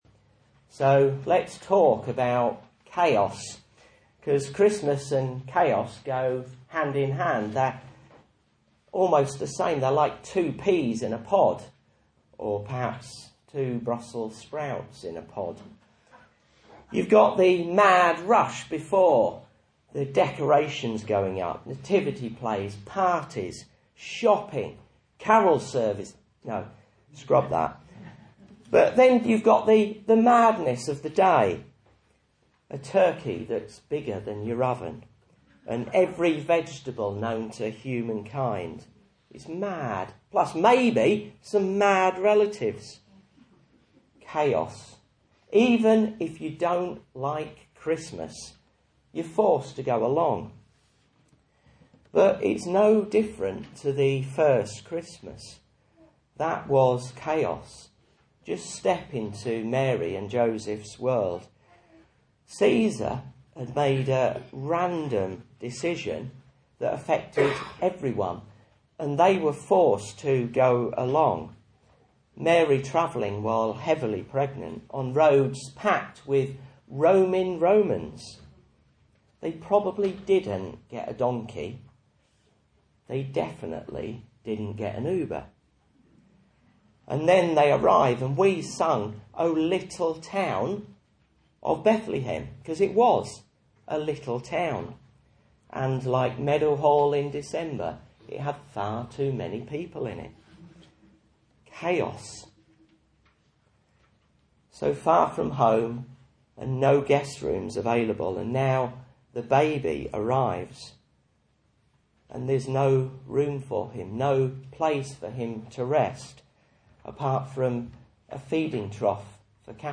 Message Scripture: Luke 2:11-12